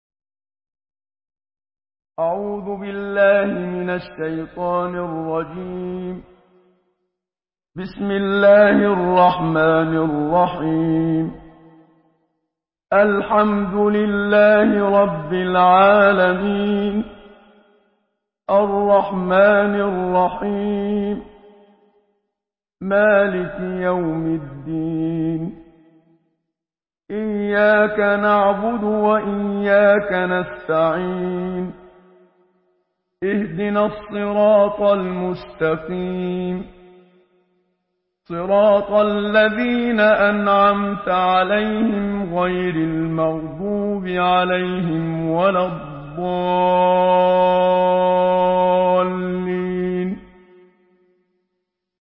سورة الفاتحة MP3 بصوت محمد صديق المنشاوي برواية حفص
مرتل